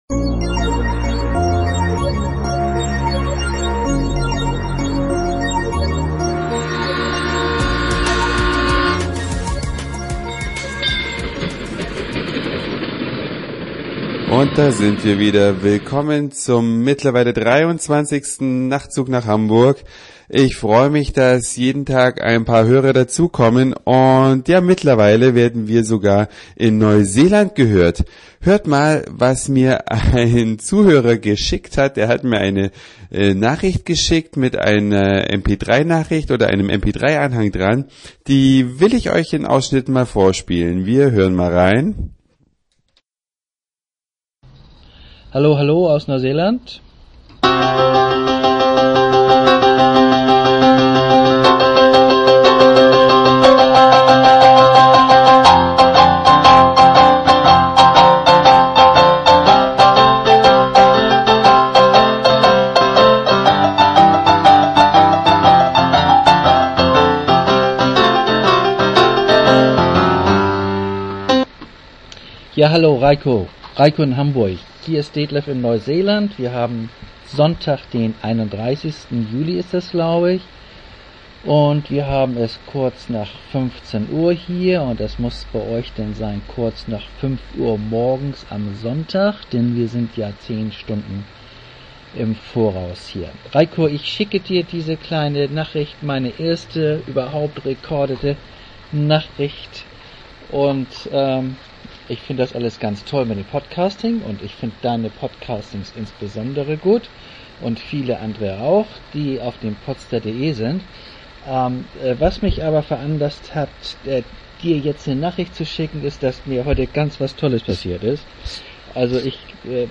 Nachtzug nach Hamburg 23 (incl. Audiogreeting)